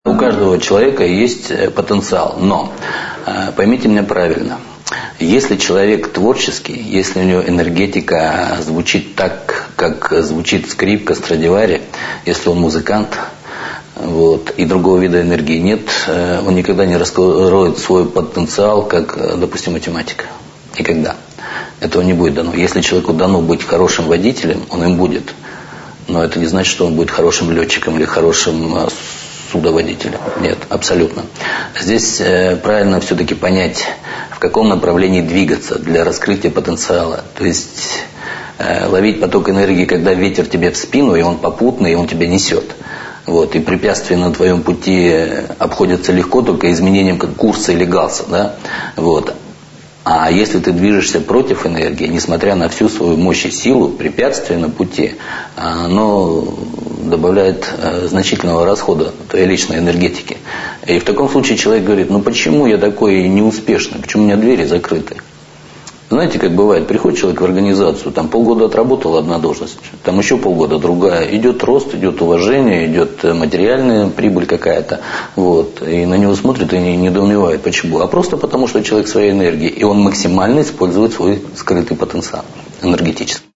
Аудиокнига: Экстрасенсы